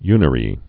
(ynə-rē)